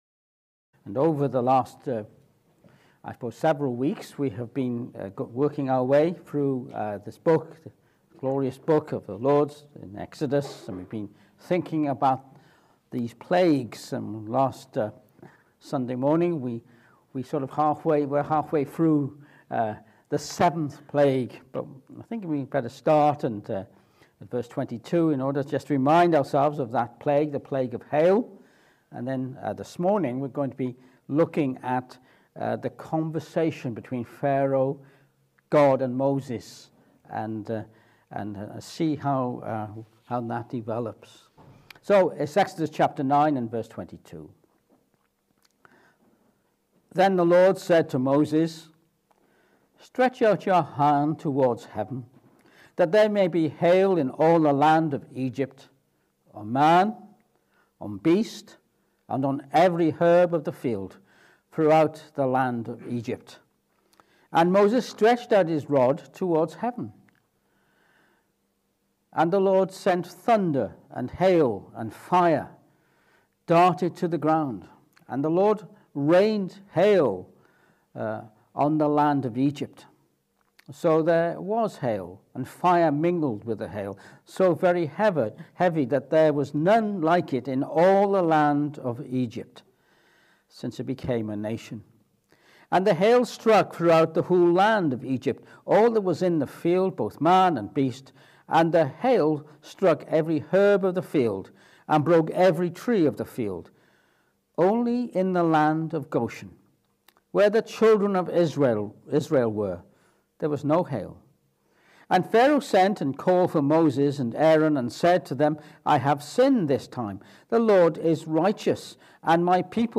Exodus 9:27-35 Service Type: Morning Service This morning we continue our study in the book of Exodus